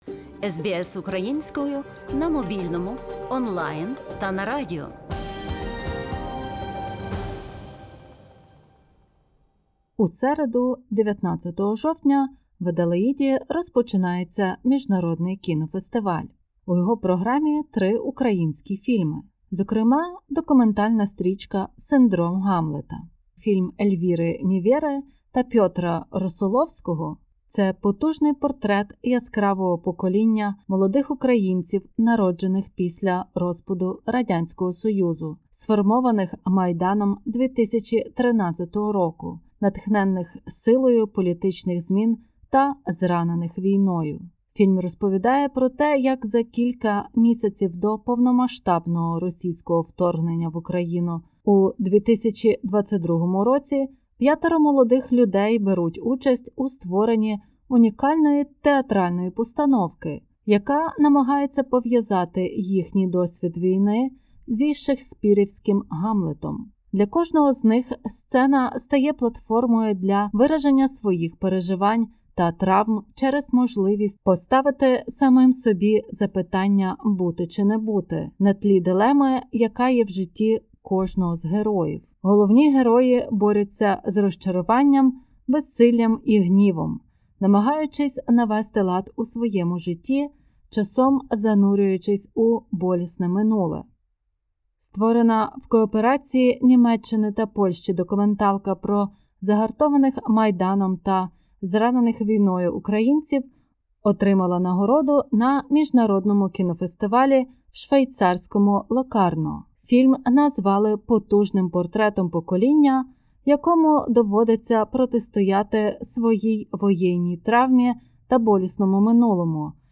У цьому подкасті розмова з одним із учасників проекту